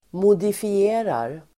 Uttal: [modifi'e:rar]